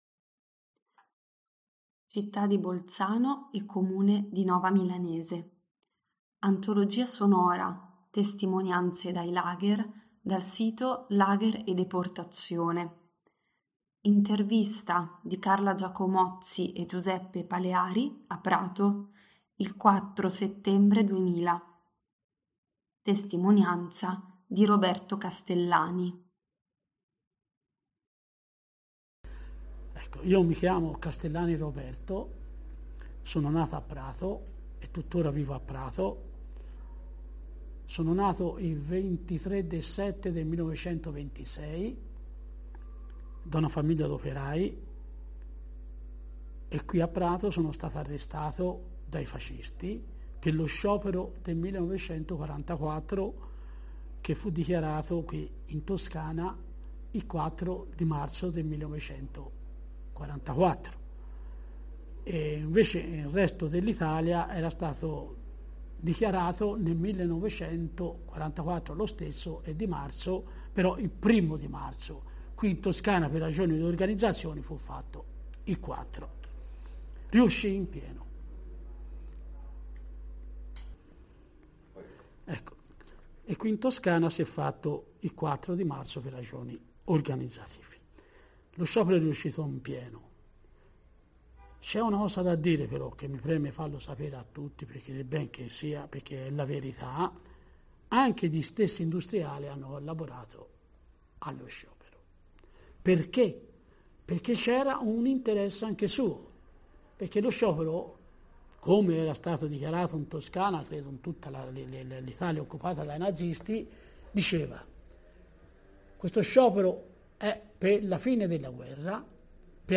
Intervista del 04/09/2000, a Prato
Si segnala la presenza di espressioni verbali riconducibili al dialetto toscano, anche in forme arcaiche.